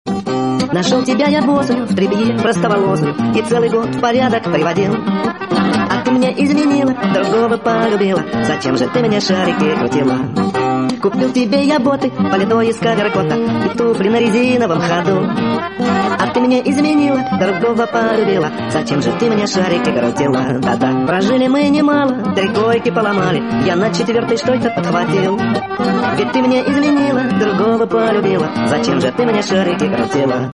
• Качество: 64, Stereo
мужской голос
веселые
Гармошка